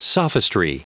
Prononciation du mot sophistry en anglais (fichier audio)